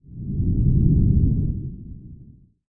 shooterAnonStep.ogg